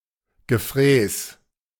Gefrees (German pronunciation: [ɡəˈfʁeːs]
De-Gefrees.ogg.mp3